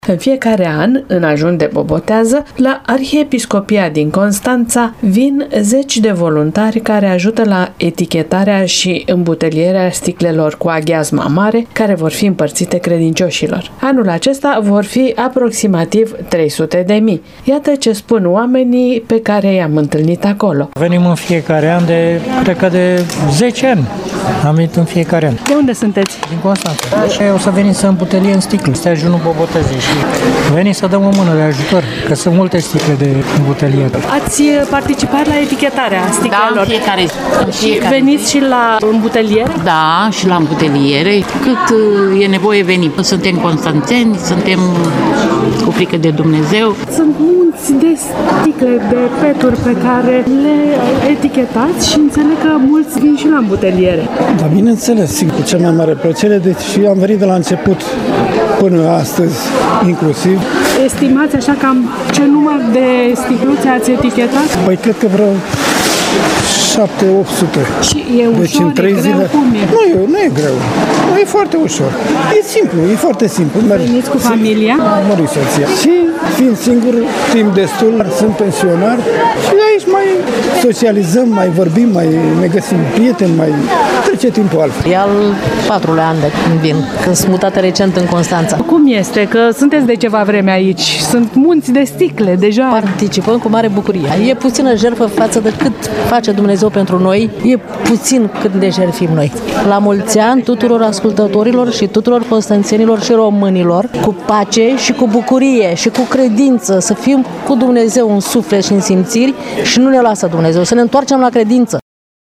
Întregul proces este posibil datorită implicării a zeci de voluntari constănțeni, de toate vârstele, care vin în fiecare an la Arhiepiscopie să ajute cu mare bucurie, după cum ne-au declarat chiar ei.